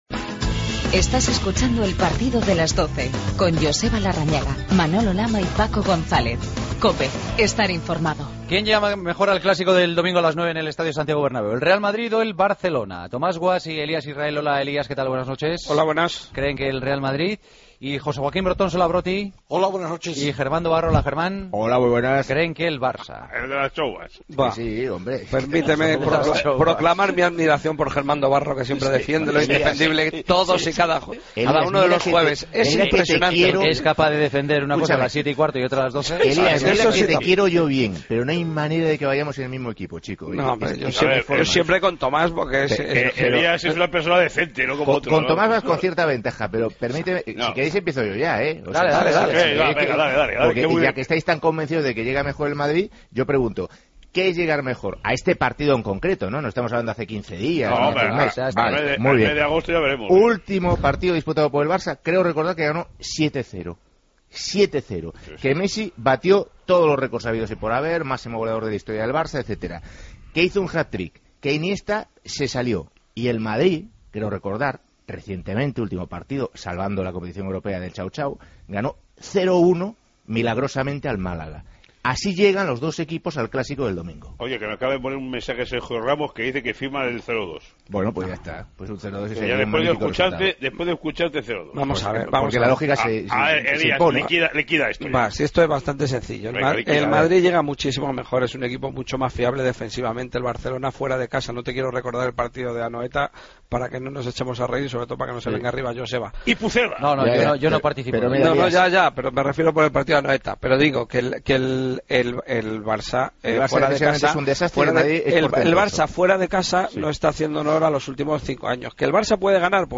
El debate de los jueves: ¿Quién llega mejor al Clásico?